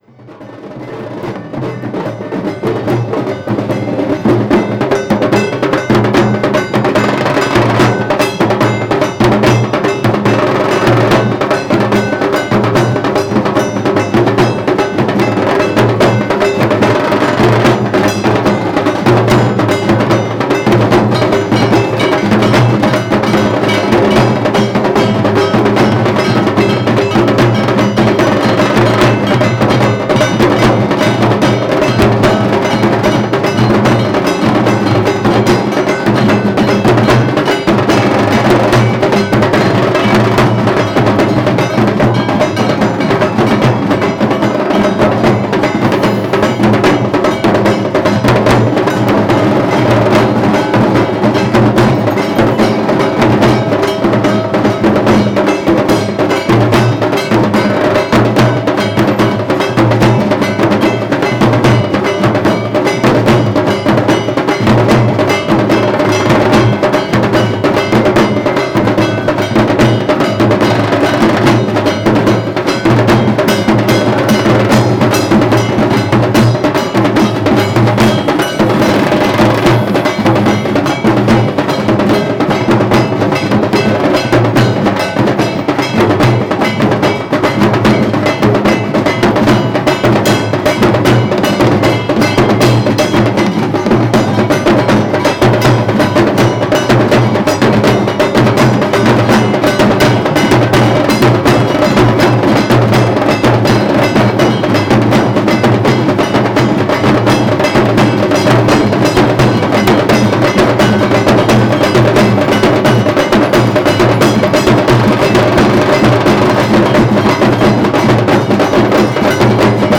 Kali旧神庙附近的Dom种姓的鼓手，录于2002年10月